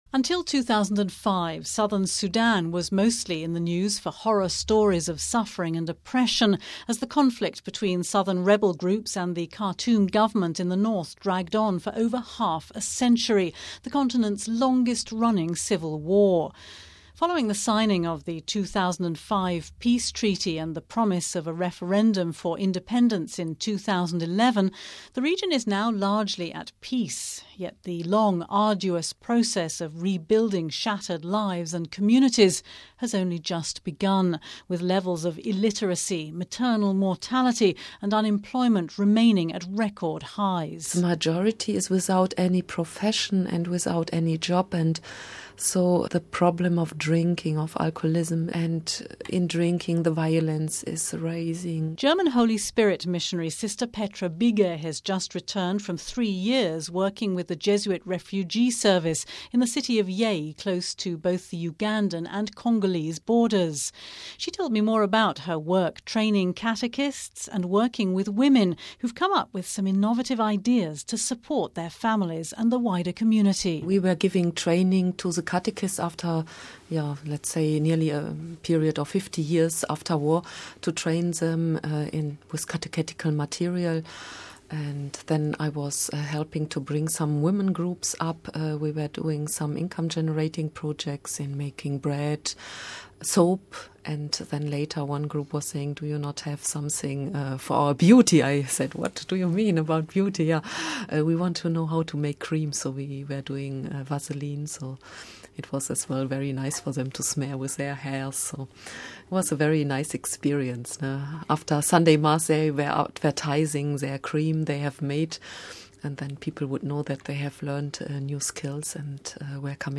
Home Archivio 2010-04-23 15:56:31 SOLIDARITY WITH SUDAN Hear from a missionary sister, just back from Southern Sudan, who's been training catechists and supporting some innovative women's income generating projects....